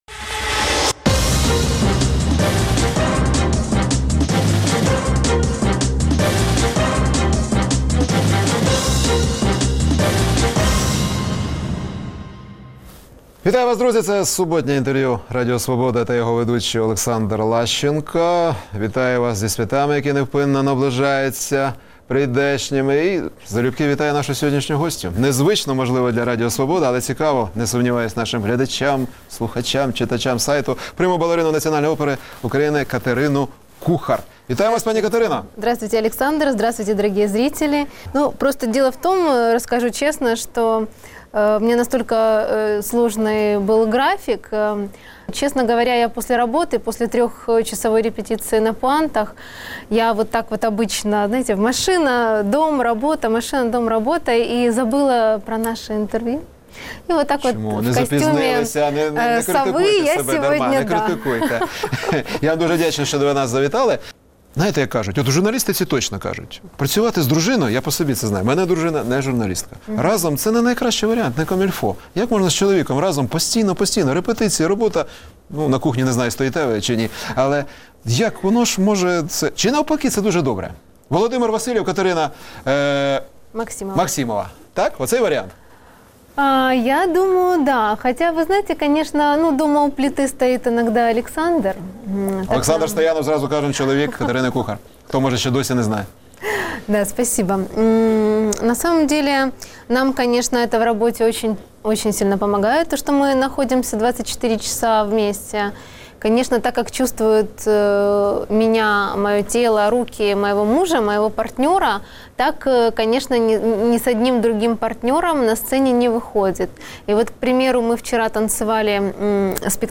Суботнє інтерв’ю - Катерина Кухар, прима-балерина Національної опери України